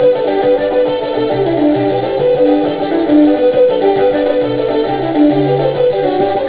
Éist linn ag canadh.....amhráin2.